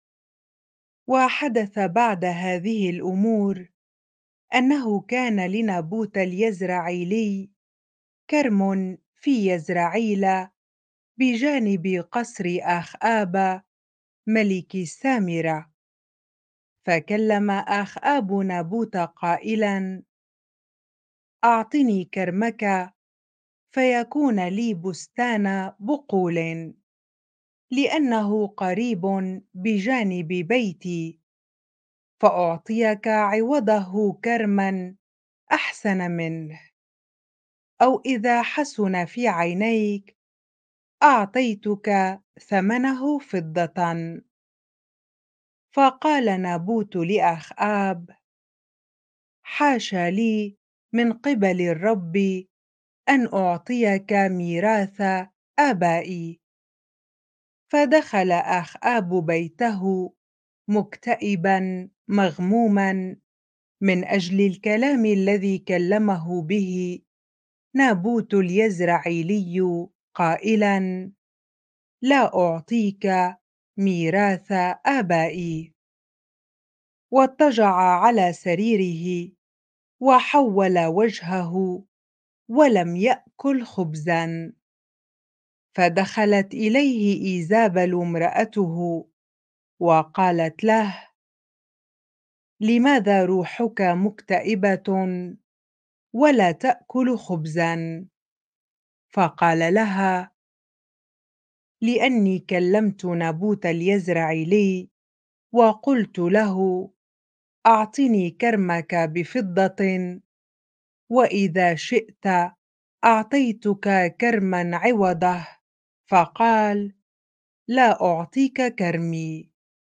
bible-reading-1 Kings 21 ar